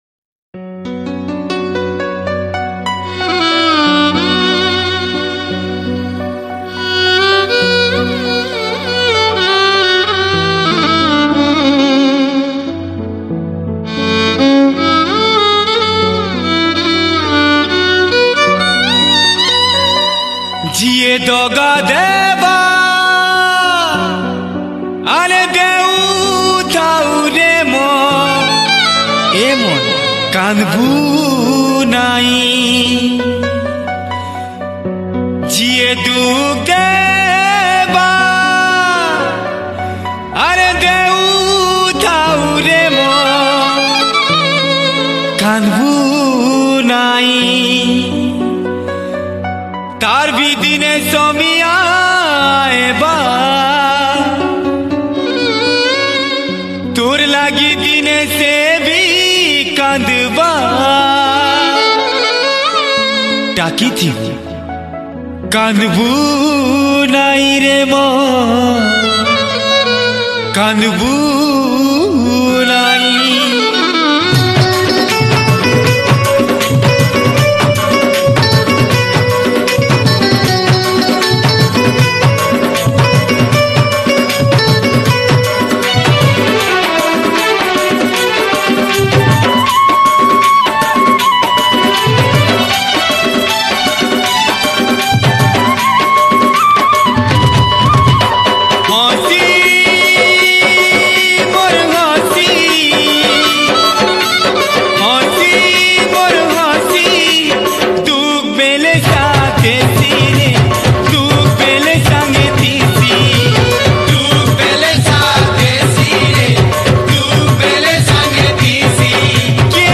Sambalpuri New Sad Song